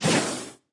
Media:Wizard_baby_atk_1.wavMedia:Wizard_base_atk_1.wav 攻击音效 atk 初级和经典及以上形态攻击音效
Wizard_baby_atk_1.wav